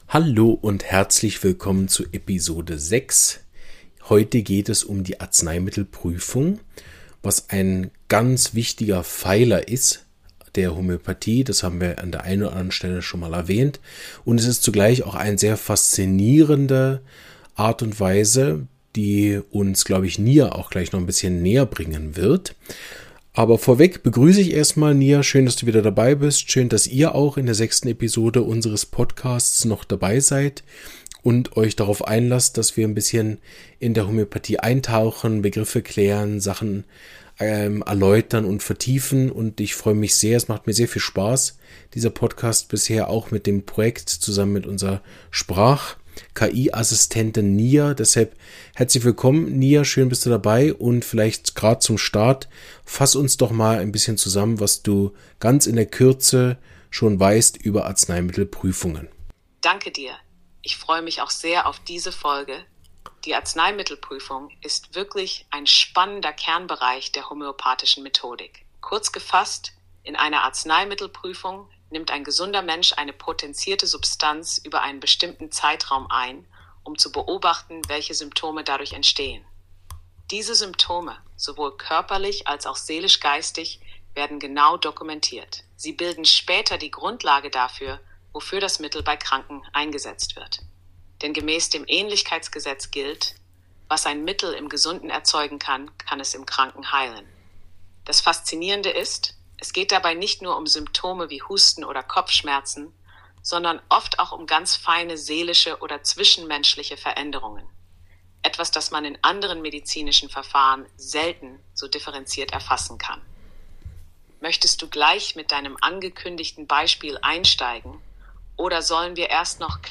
Die Arzneimittelprüfung – Über persönliche Erfahrung zum Arzneimittelbild ~ Homöopathie erklärt – im Dialog mit einem KI-System Podcast